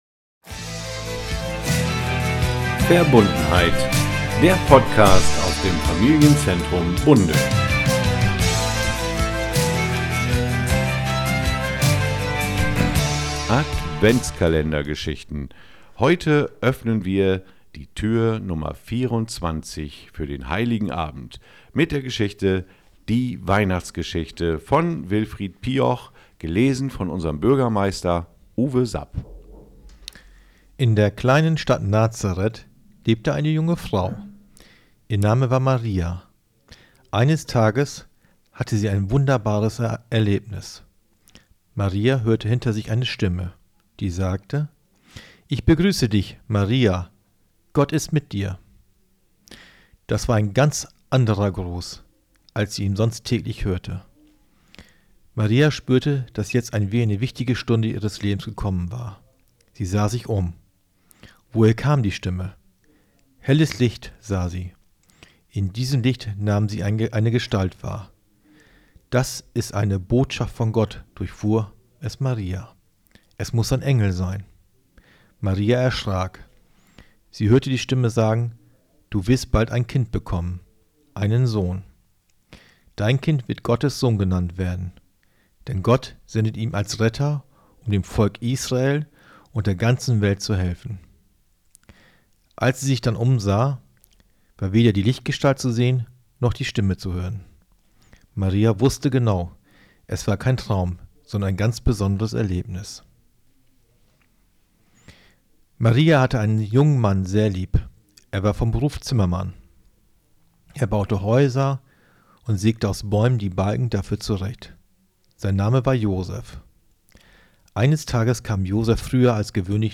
Gelesen von Bürgermeister Uwe Sap